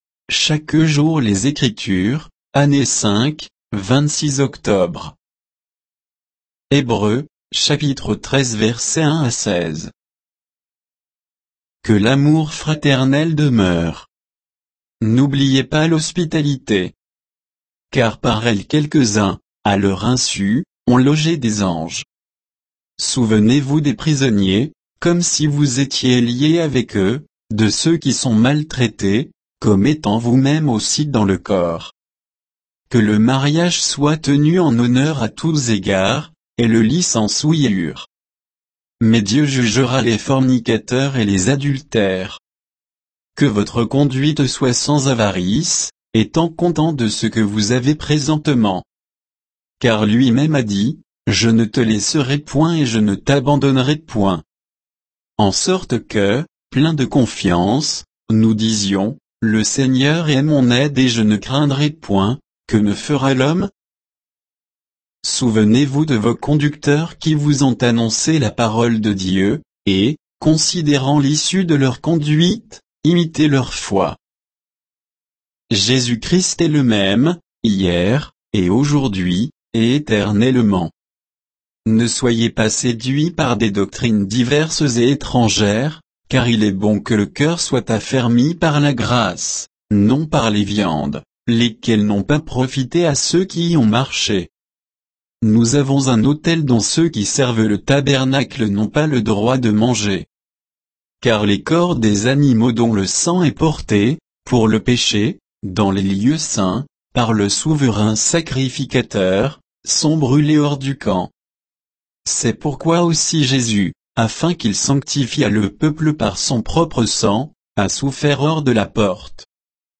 Méditation quoditienne de Chaque jour les Écritures sur Hébreux 13